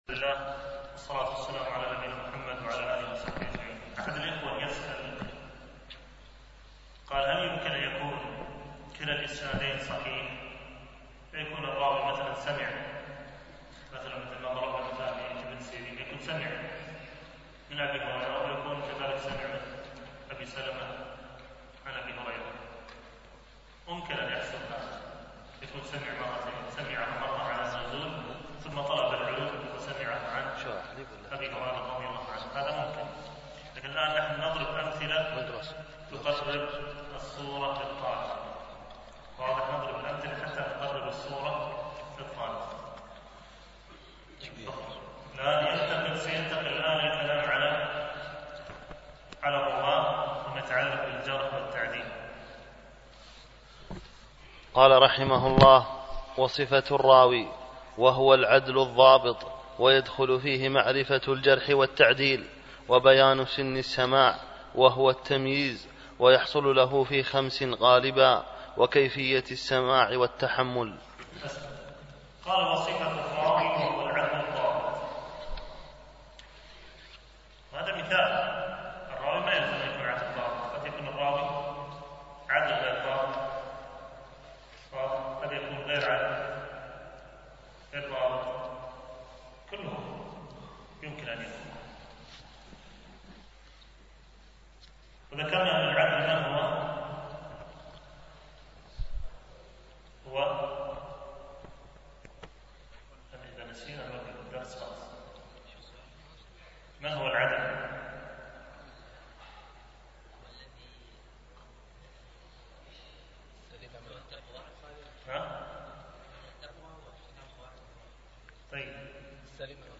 الدورة: دورة الإمام مالك العلمية الثانية بدبي (10-13 رجب 1436هـ)
شرح التذكرة في علوم الحديث ـ الدرس الرابع الألبوم: دروس مسجد عائشة (برعاية مركز رياض الصالحين ـ بدبي) المدة
التنسيق: MP3 Mono 22kHz 32Kbps (CBR)